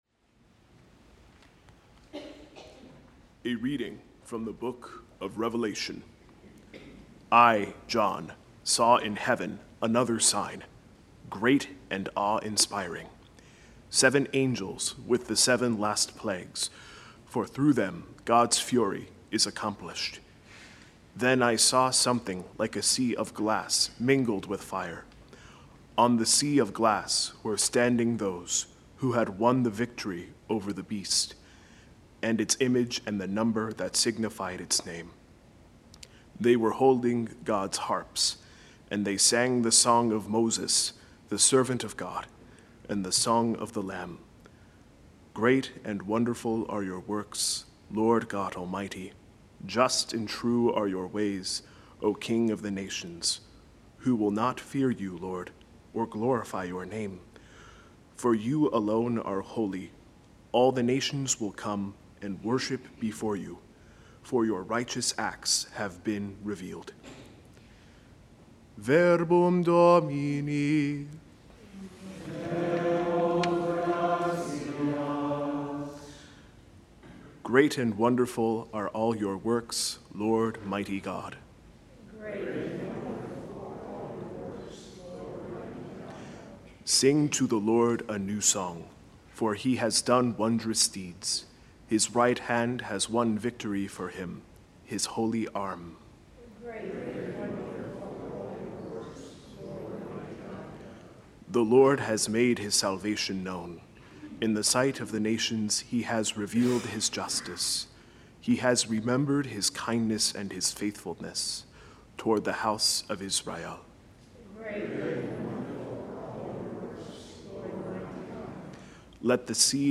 A homily for Christ the King 2024